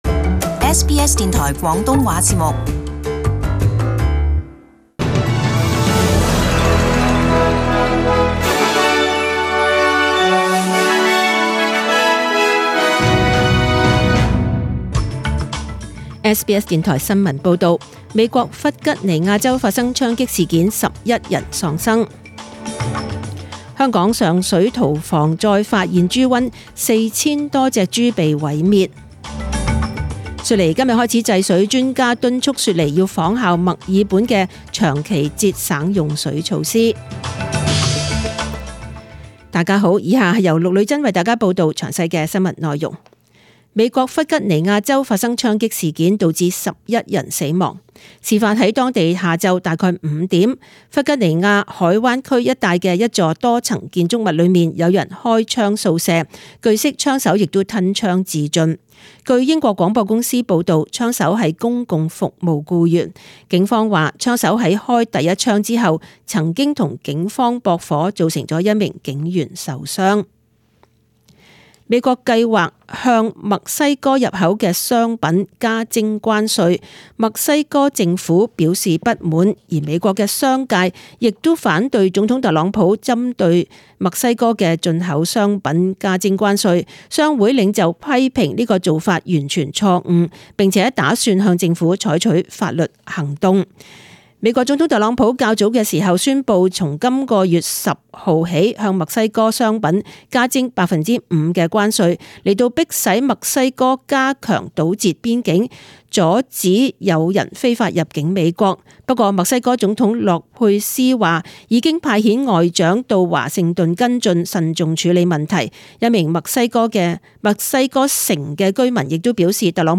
請收聽本台為大家準備的詳盡早晨新聞
Chinese (Cantonese) News Source: SBS News